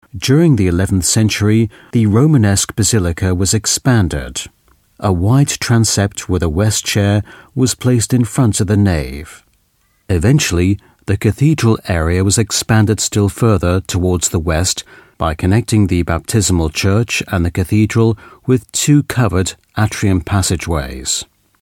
Native Speaker
Audioguides